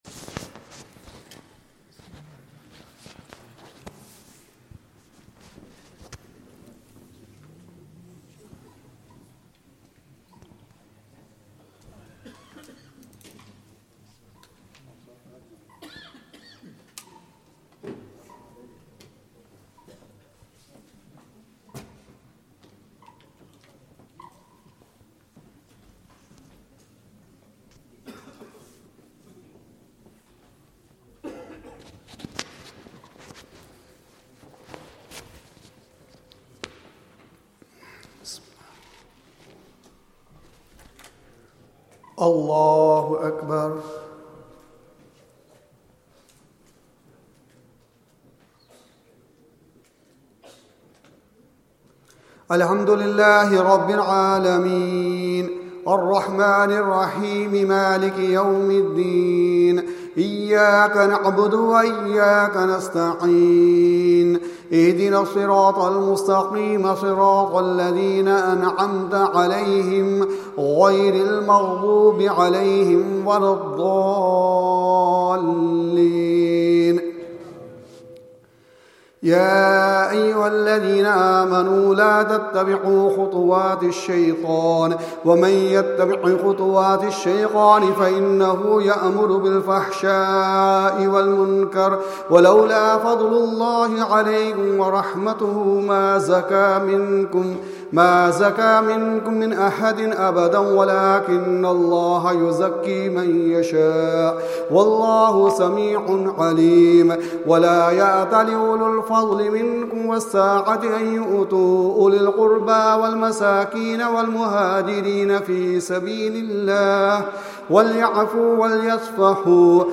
Zakariyya Masjid Motherwell | Taraweeh | eMasjid Live
Taraweeh